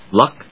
/lˈʌk(米国英語)/